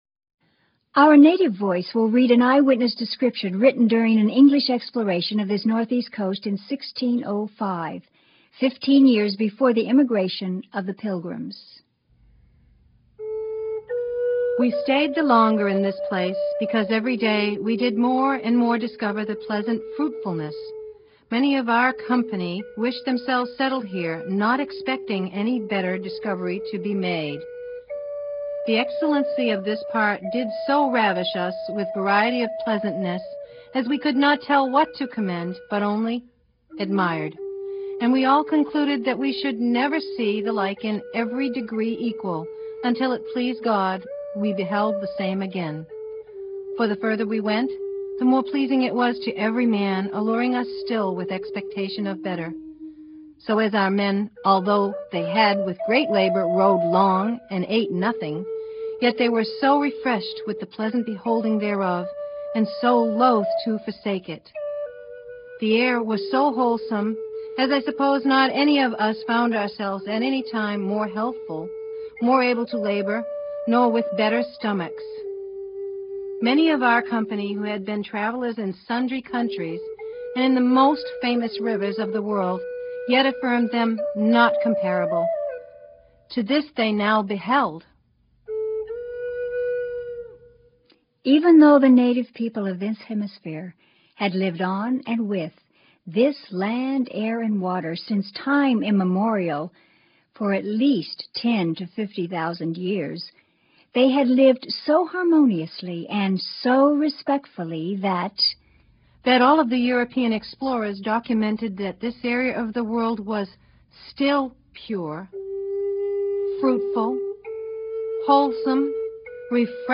This 90 minute audio-program is divided into 4 sections and features historically relevant music and 3 speakers.